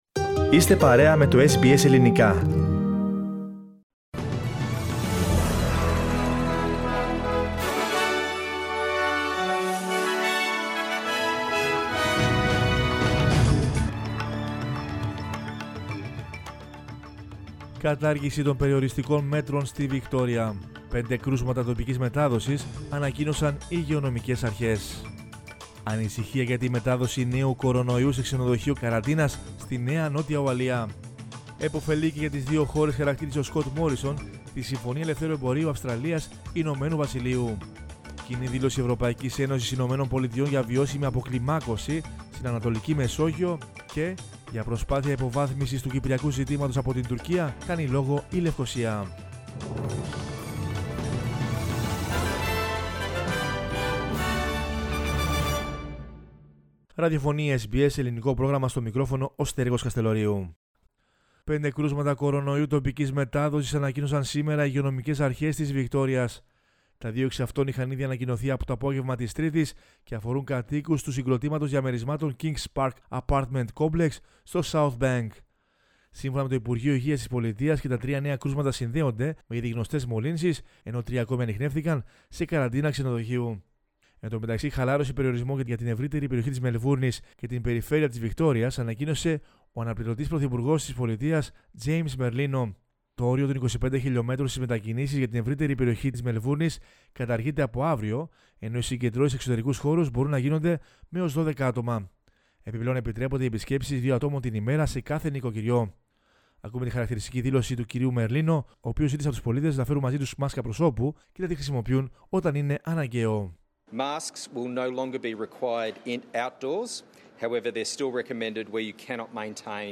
News in Greek from Australia, Greece, Cyprus and the world is the news bulletin of Wednesday 16 June 2021.